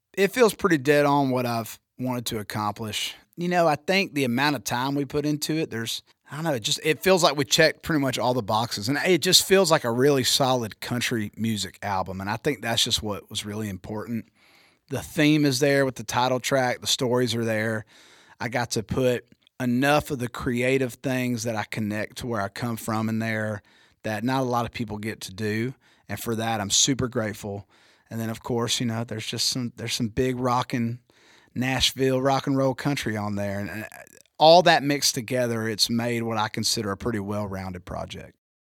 Travis Denning talks about his first full-length album.